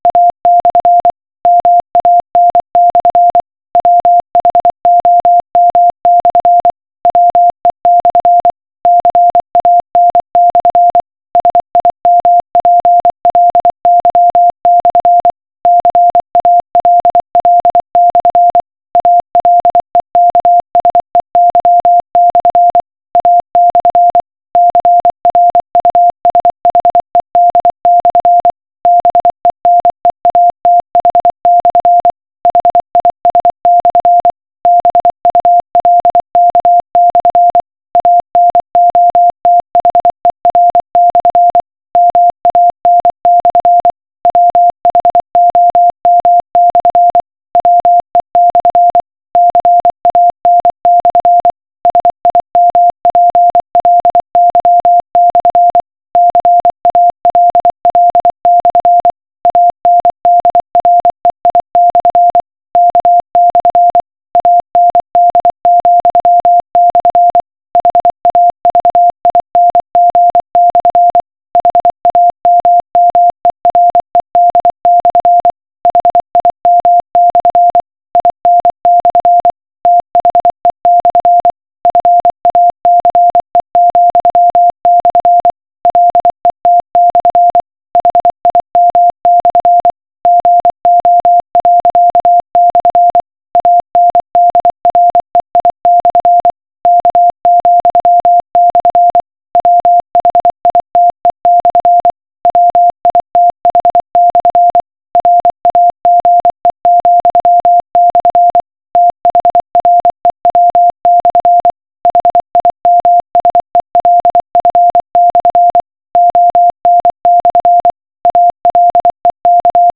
Die Übersetzung des Textes ist auf Morse Code und kann unten diesem Satz im Anhang als Audio File gefunden werden.